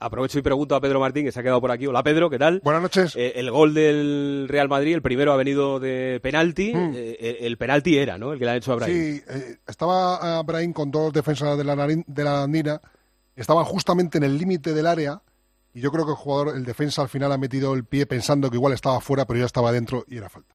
Así lo argumentaba en Tiempo de Juego.